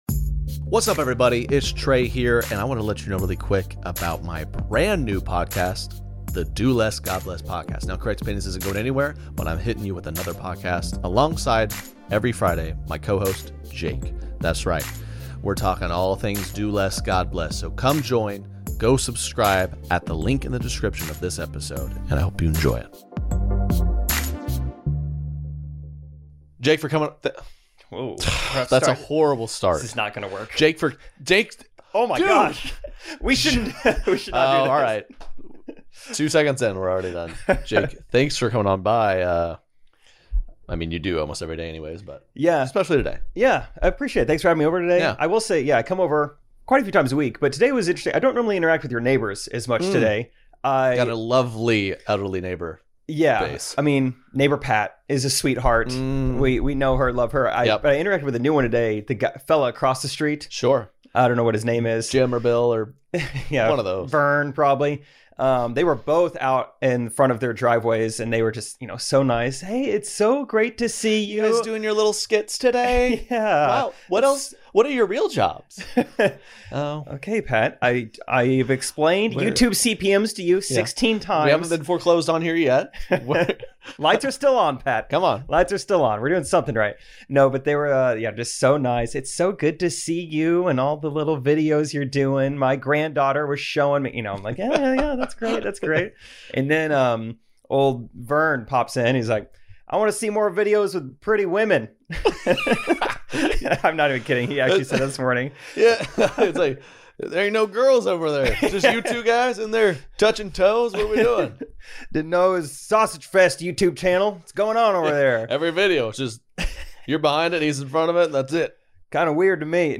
The Do Less God Bless podcast breaks down their bizarre social media comedy lives, the "Do Less" moments happening all around us, and much more. Tune in to this weekly, family-friendly comedic show every Friday as these two fairly mature 20 somethings in the midwest give their takes on what's happening today.